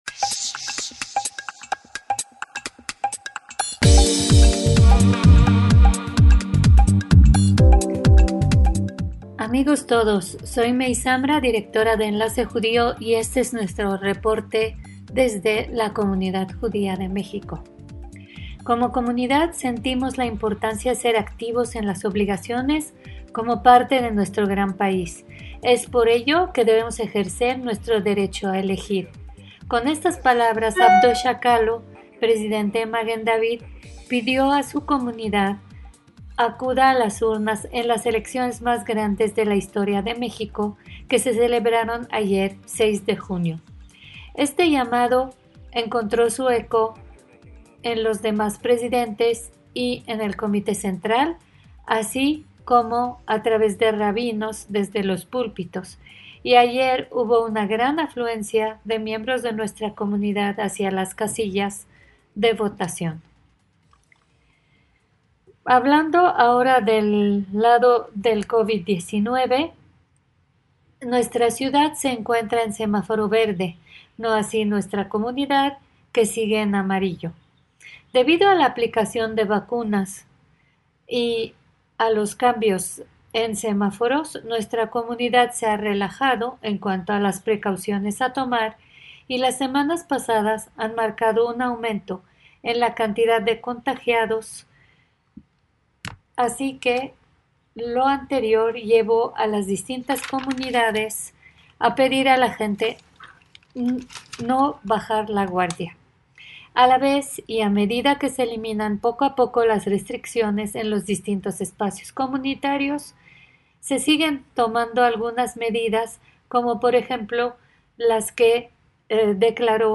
DESDE MÉXICO, CON ENLACE JUDÍO - El domingo pasado se celebraron unas elecciones, en las cuales la comunidad judía tuvo gran participación; líderes de la comunidad pidieron a los correligionarios no bajar la guardia frente al Covid-19; personalidades judío mexicanas destacadas y más en este reporte.